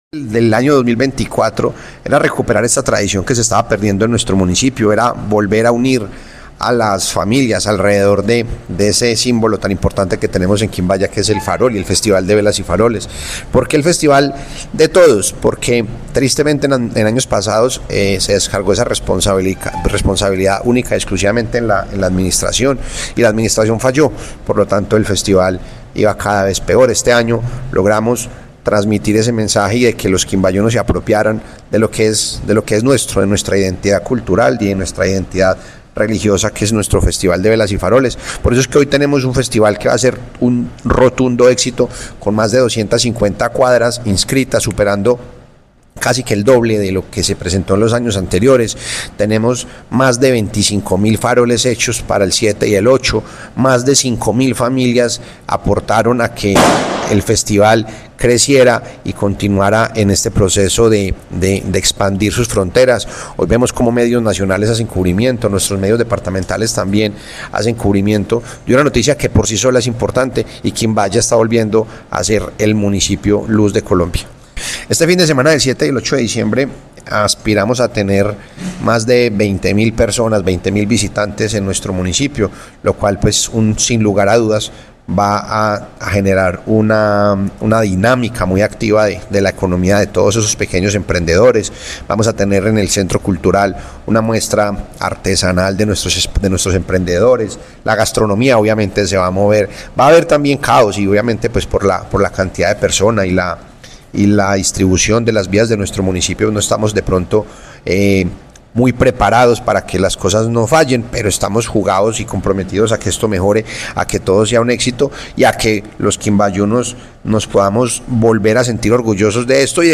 Juan Manuel Rodríguez, alcalde de Quimbaya, Quindío